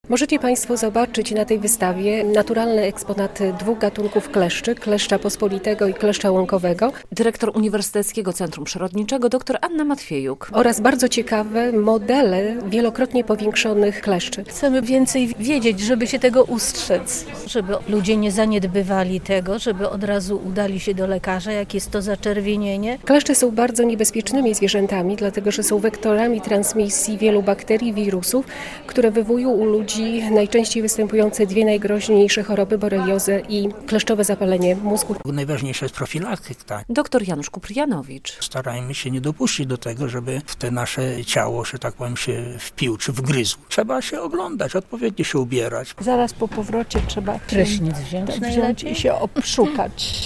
Wystawa o kleszczach - relacja